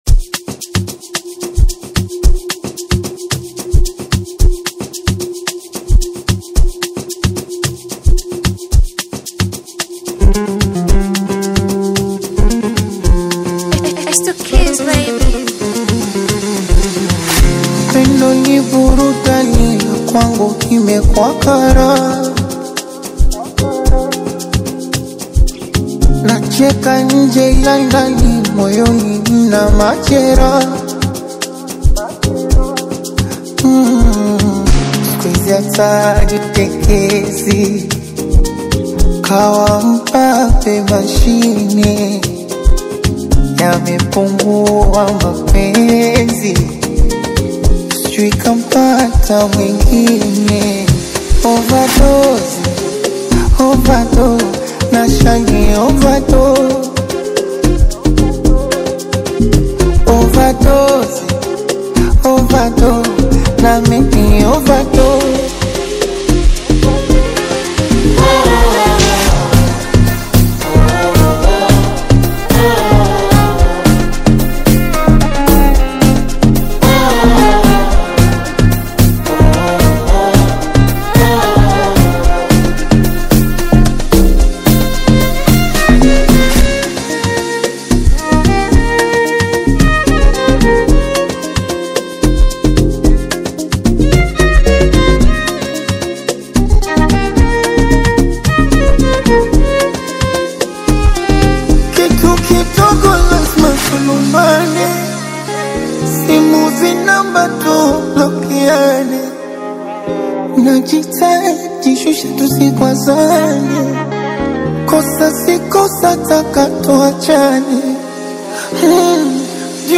Amapiano Music